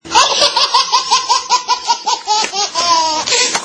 Las risas de bebé
AUDIO: La mejor respuesta para muchos momentos de Tiempo de Juego... las risas de bebé.